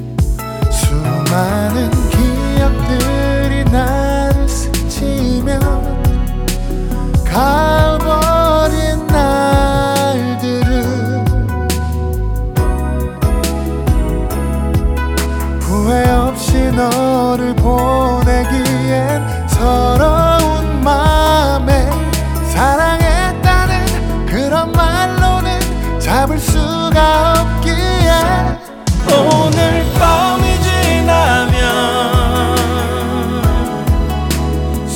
Жанр: Поп музыка / R&B / Соул